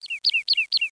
BIRD5.mp3